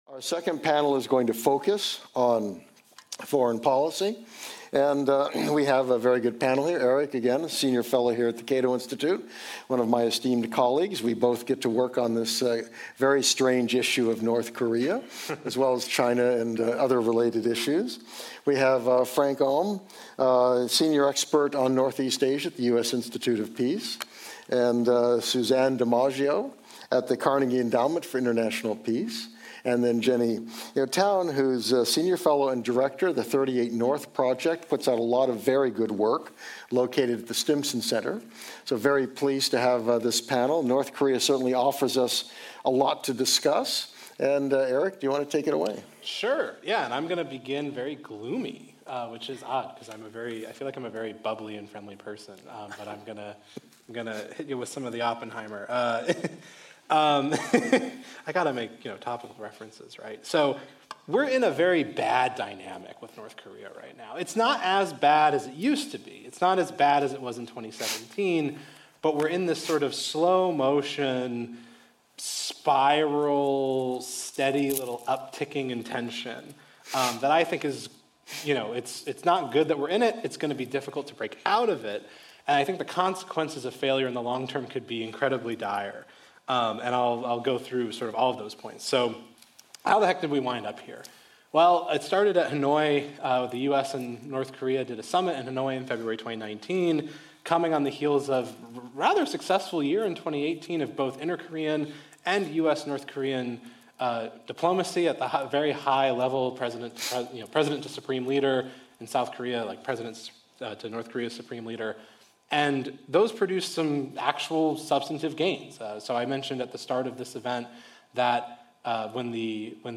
Download: Video Audio Events • July 19, 2023 Thawing a Frozen Conflict: The Korean War Armistice at 70 Years — Panel 2: Can the United States and North Korea Get to a Peace Regime?